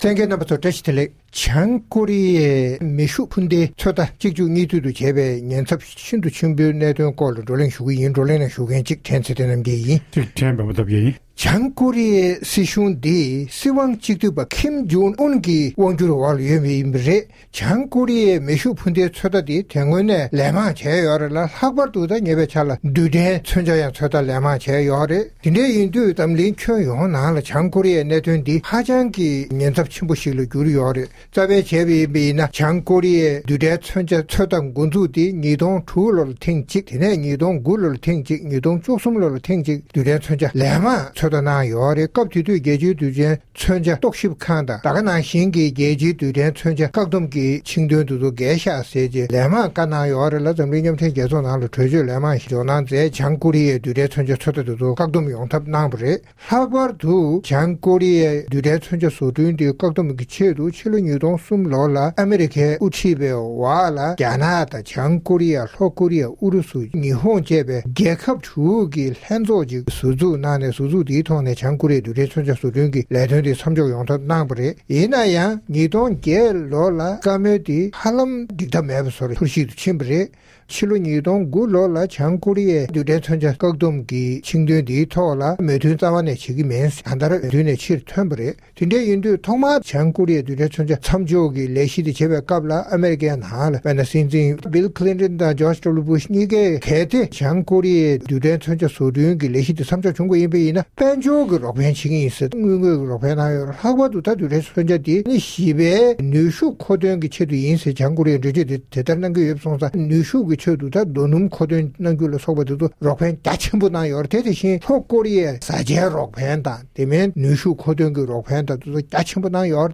༄༅༎ཐེངས་འདིའི་རྩོམ་སྒྲིག་པའི་གླེང་སྟེགས་ཞེས་པའི་ལེ་ཚན་ནང་། བྱང་ཀོ་རི་ཡས་མེ་ཤུགས་འཕུར་མདེལ་ཚོད་ལྟ་གཅིག་མཇུག་གཉིས་མཐུད་དུ་བྱས་དང་བྱེད་བཞིན་པ་དང་། དེ་བཞིན་རྡུལ་ཕྲན་མཚོན་ཆ་ཚོད་ལྟ་བྱེད་གྲབས་ཡོད་པའི་ཛ་དྲག་གནས་སྟངས་སྐོར་རྩོམ་སྒྲིག་འགན་འཛིན་རྣམ་པས་བགྲོ་གླེང་གནང་བ་ཞིག་གསན་རོགས་གནང་།།